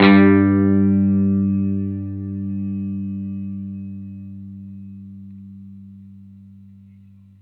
R12 NOTE  G.wav